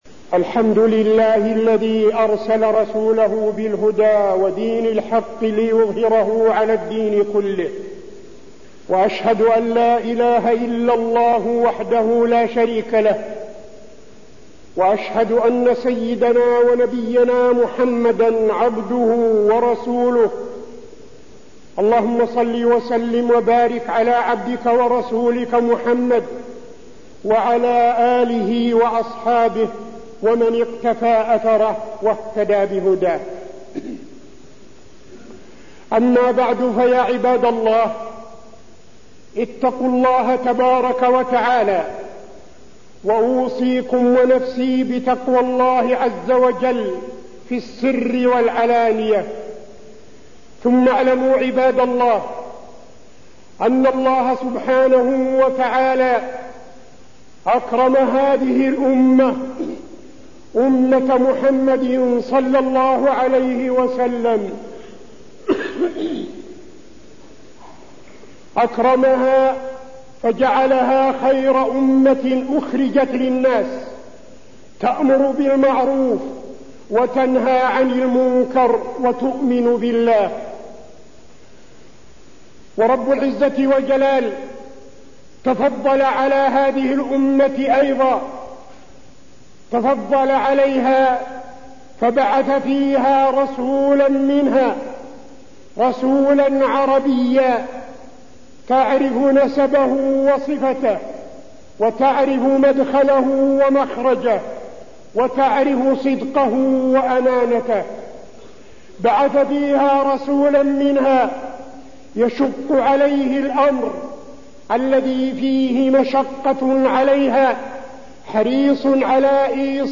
خطبة اتباع الرسول صلى الله عليه وسلم وفيها: تفضل الله على الأمة أن بعث فيها رسول منها، ومحبة الله مقرونة باتباع الرسول، خصال اتباع الرسول ﷺ
تاريخ النشر ١٢ ربيع الأول ١٤٠٤ المكان: المسجد النبوي الشيخ: فضيلة الشيخ عبدالعزيز بن صالح فضيلة الشيخ عبدالعزيز بن صالح اتباع الرسول صلى الله عليه وسلم